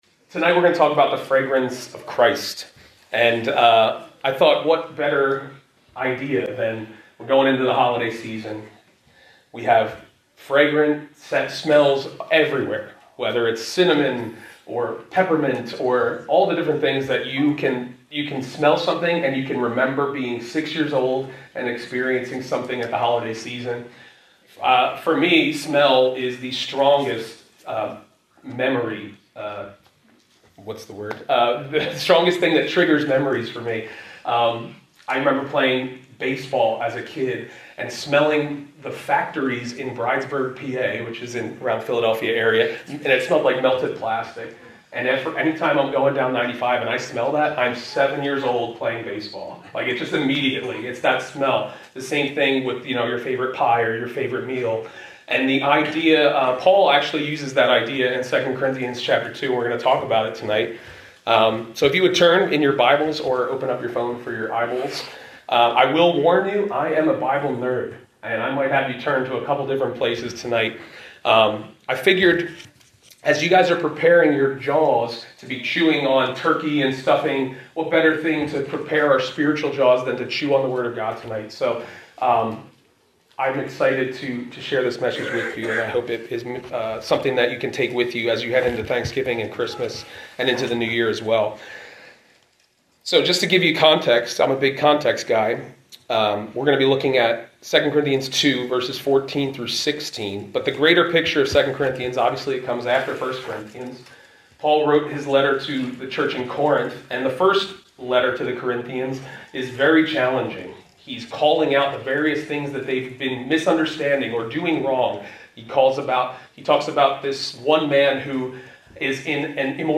I was asked to speak at my church’s Thanksgiving Eve Service this year. It’s been almost 8 years since I spoke to an entire congregation so I was very grateful for the opportunity to share with Impact Church in Parkesburg, PA.